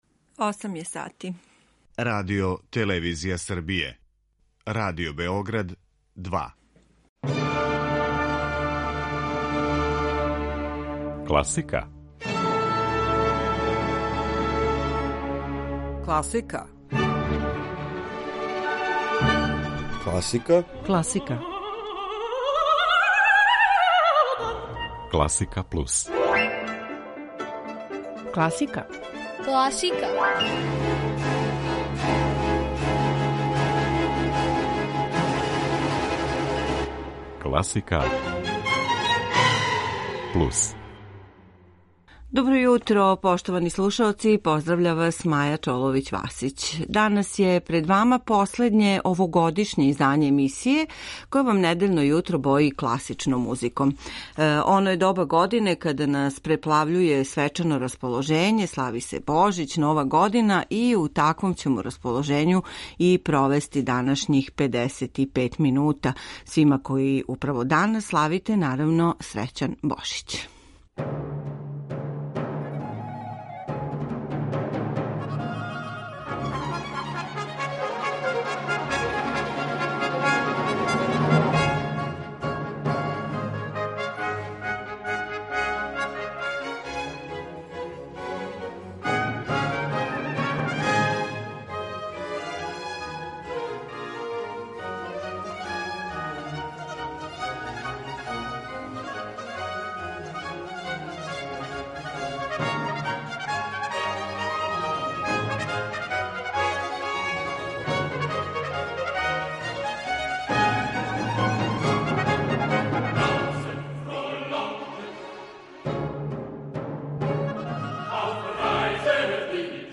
Међу извођачима су Енглески барокни солисти и Џон Елиот Гардинер, тенор Лучано Павароти, виолиниста Данијел Хоуп, пијаниста Алфред Брендел, као и Филхармонија и Дечји хор из Лос Анђелеса, и диригент Густаво Дудамел.